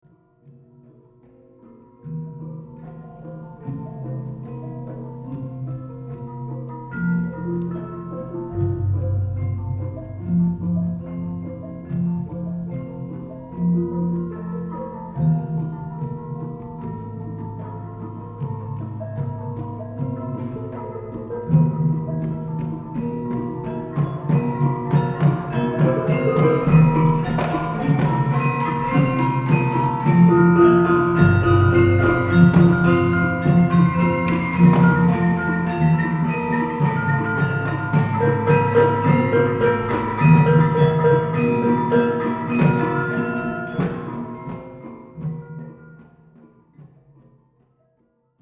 Javanese Gamelan music: Playon Gambuh
Played by Kyai Telågå Rukmi, the University of Wisconsin-Madison Javanese gamelan ensemble, on 21 April 2001.
This short excerpt in the pélog scale is from a duel scene from a dance duet called "Beksan Wayang Golek Kelaswara-Adaninggar". In this piece, I am playing the bonang barung. The excerpt starts with a short section played in the imbal or interlocking style for bonang. About halfway through the drum signals a louder section played in a different style; this marks some of the duel fighting.